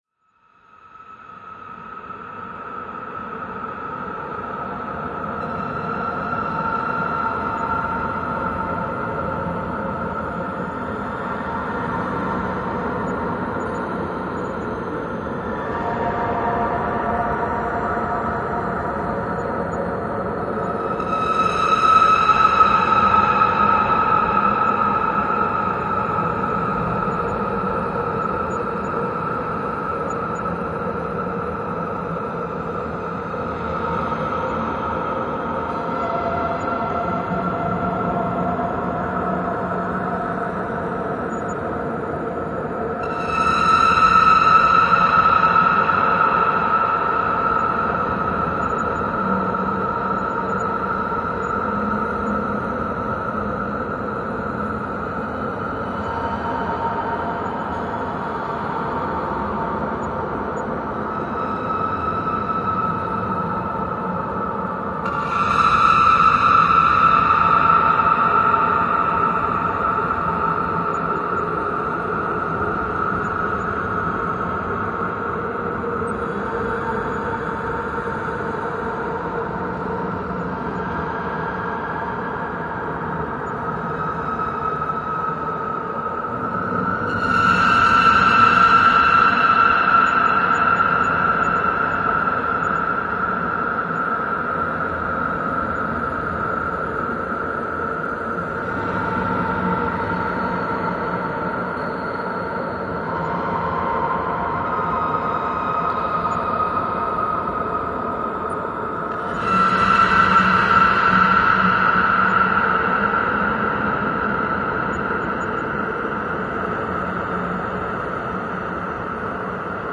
恐怖 " 令人毛骨悚然的恐怖声音效果
描述：令人毛骨悚然的恐怖效果对于能干制作的恐怖电影有益
Tag: SFX SoundEffect中 sounddesign 音景 声音